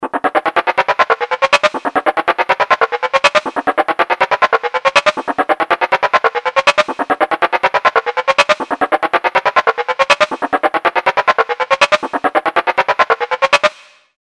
Ascending.wav